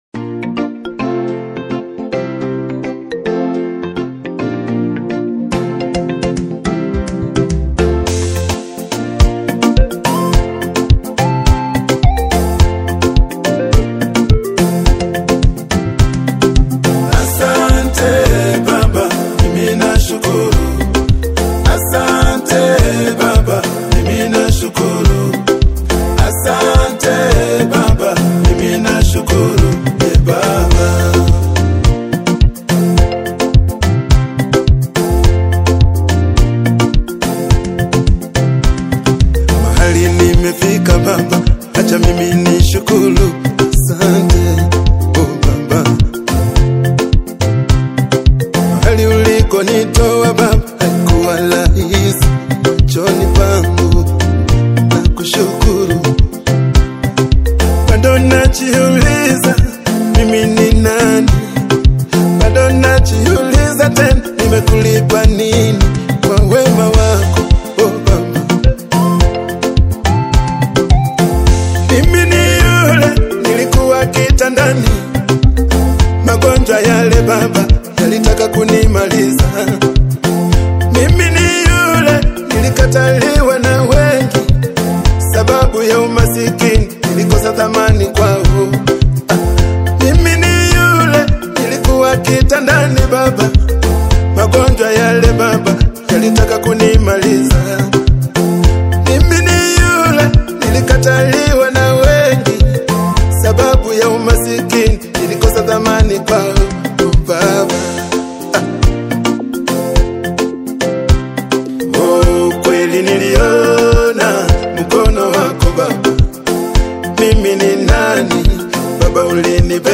a soulful and reflective new single